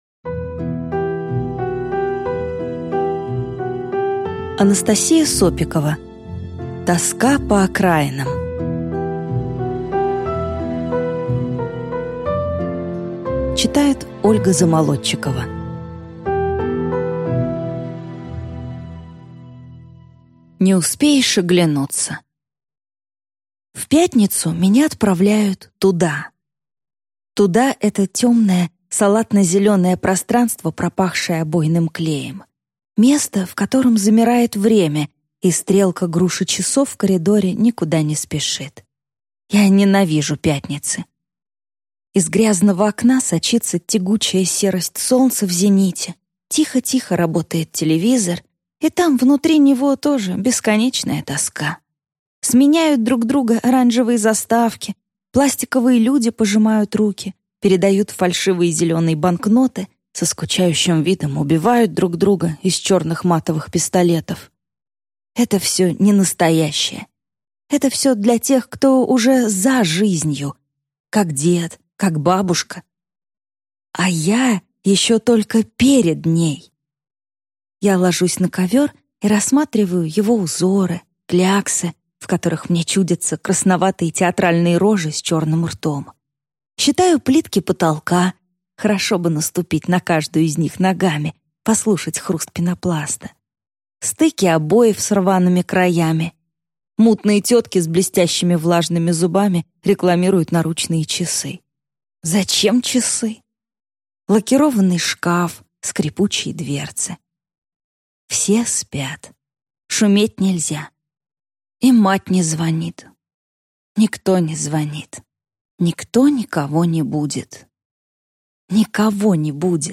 Аудиокнига Тоска по окраинам | Библиотека аудиокниг